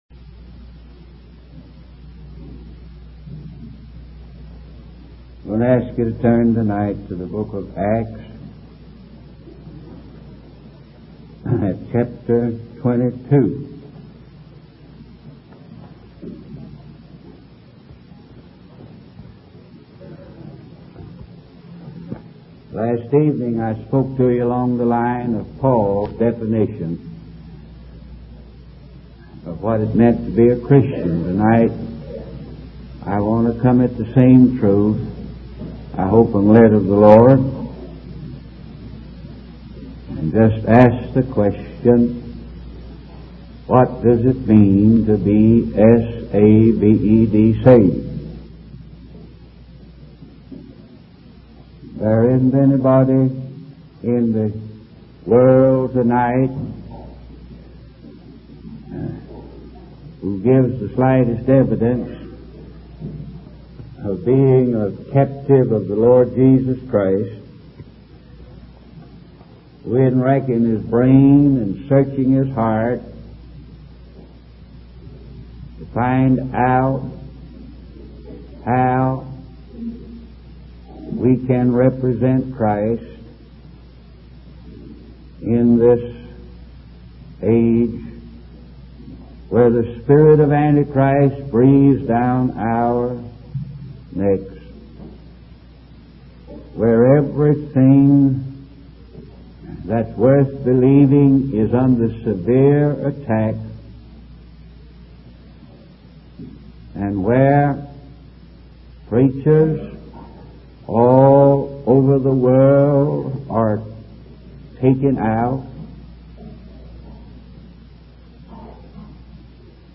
In this sermon, the preacher emphasizes the importance of being a witness for God.